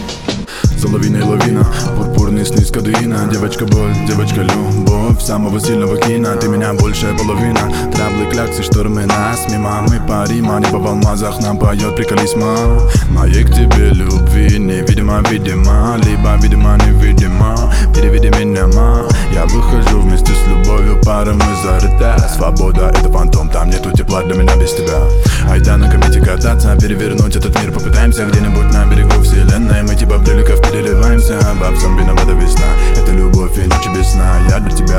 Жанр: Рэп и хип-хоп / Иностранный рэп и хип-хоп / Русские